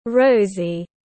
Rosy /’rəʊzi/